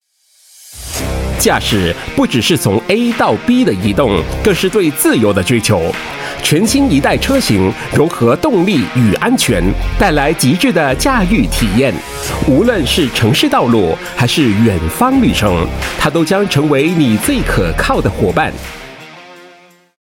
Voice Samples: Automotive
male